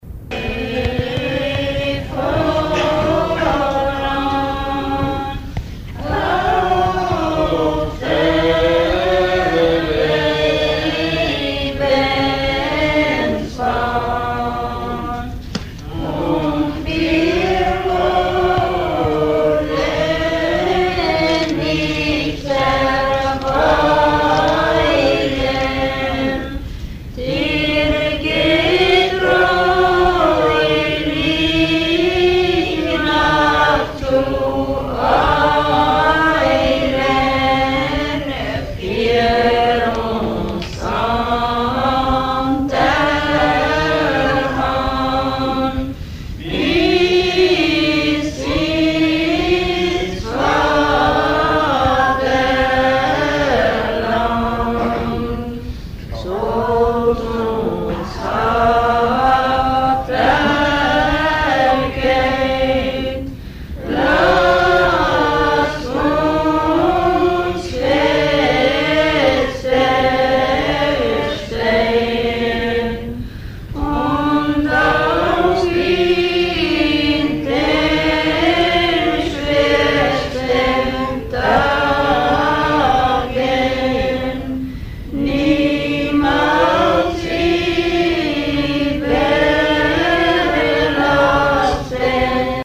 При этом в общине выделяются несколько человек, выполняющих функцию запевалы.
Преобладающей фактурой при хоровом исполнении духовных песен в церкви является унисонное пение, что соответствует протестантскому хоралу в его изначальном понимании.
01 Духовная песня «Jesu, geh vоrаn auf der Lebensbahn» («Иисус, веди нас по жиз-ненному пути») в исполнении прихожан лютеранской церкви с. Подсосново Немецко-го НР Алтайского края